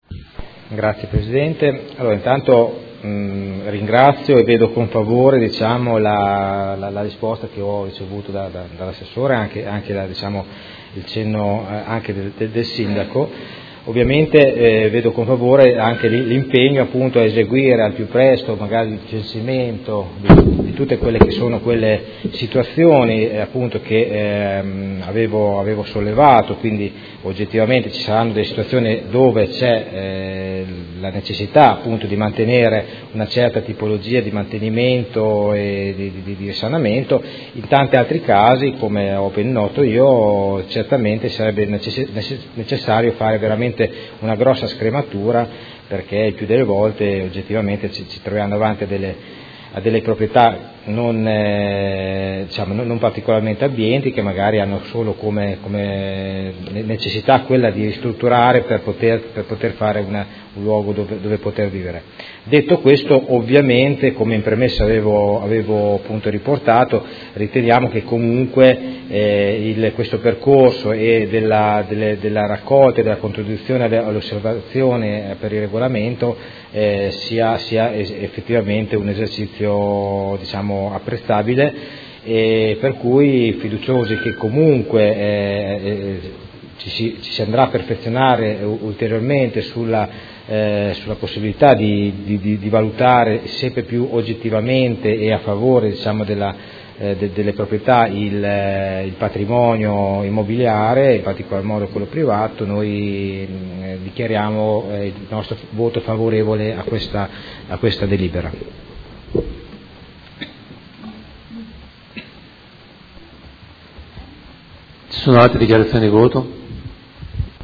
Seduta del 7 novembre 2019. Dichiarazioni di voto su proposta di deliberazione: Variante normativa al Piano Operativo Comunale (POC) e al Regolamento Urbanistico Edilizio (RUE) – Controdeduzioni e approvazione ai sensi degli artt. 33 e 34 della L.R. 20/2000 e s.m.i; ed emendamento Prot. Gen. n 329505